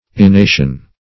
Enation \E*na"tion\, n. (Bot.)